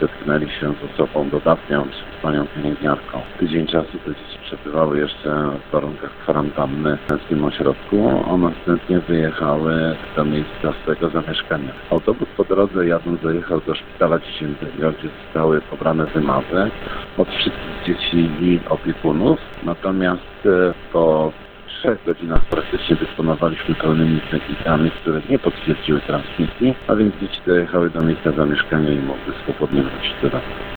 Jak mówi Janusz Dzisko, Warmińsko-Mazurski Państwowy Wojewódzki Inspektor Sanitarny, cała grupa w drodze powrotnej zajechała do olsztyńskiego szpitala.